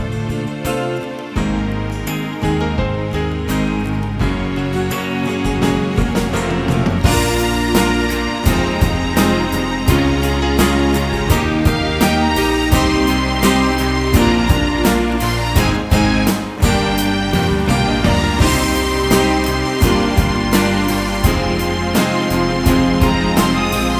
No Guitars Pop (2000s) 3:45 Buy £1.50